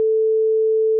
sine.wav